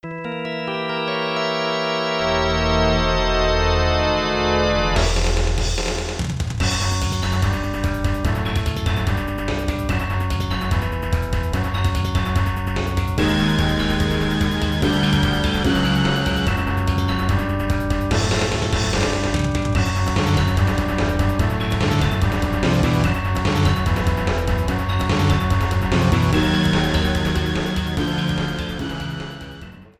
Fade out added
Fair use music sample